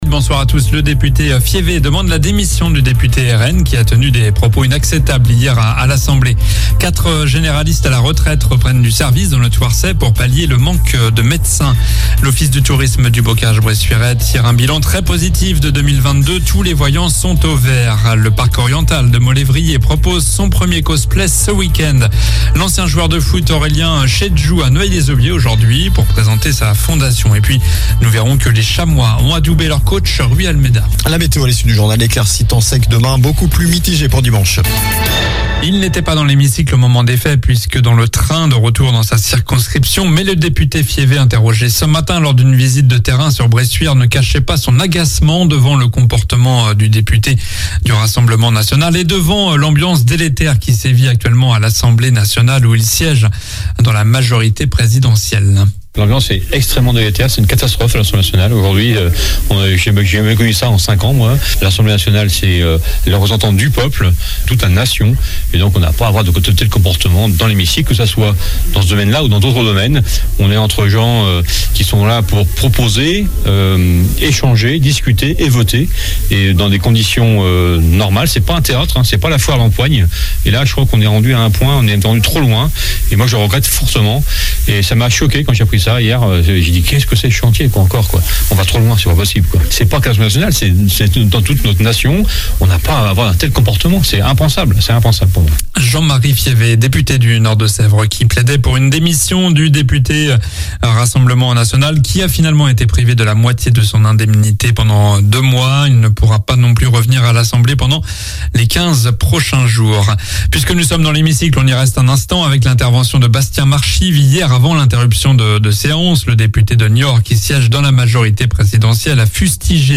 Journal du vendredi 04 novembre (soir)